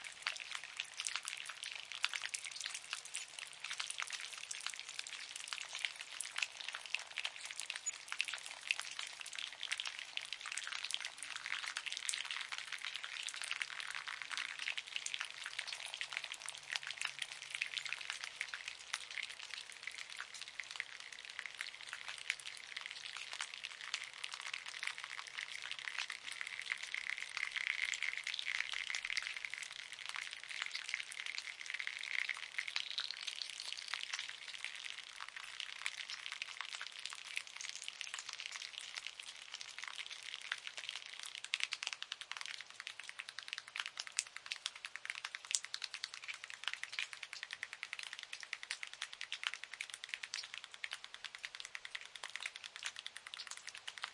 描述：听雨是如何落下蚂蚁打湿树叶的
Tag: 湿